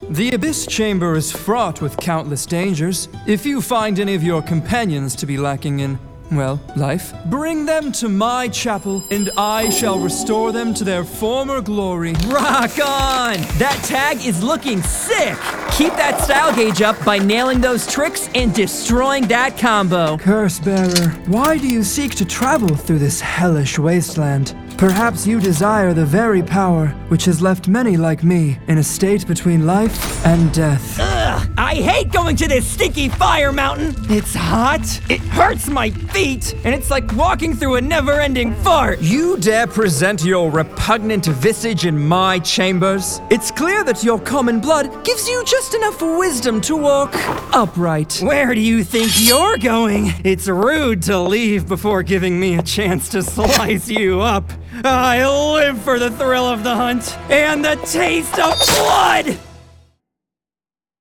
My Voice Demos
Video Game
My home recording studio is fully equipped to deliver professional-grade audio. I use a Neumann TLM-103 microphone paired with a Focusrite audio interface, capturing crystal-clear vocals with precision. Combined with top-of-the-line software, my setup ensures broadcast-quality recordings that meet industry standards for voice-over, ADR, and more.